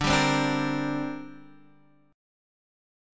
D7sus4#5 chord